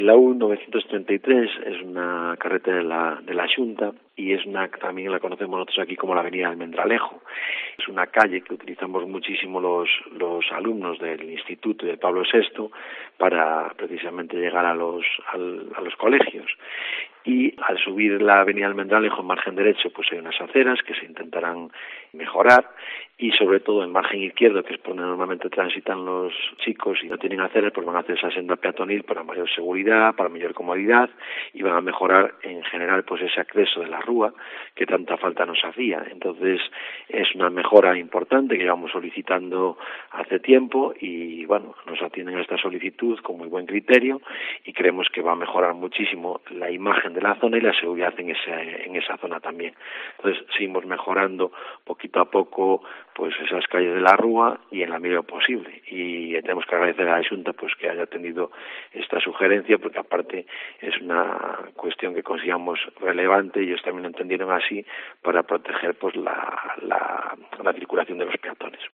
Declaraciones del alcalde de A Rúa, Álvaro Fernández.